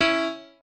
piano4_11.ogg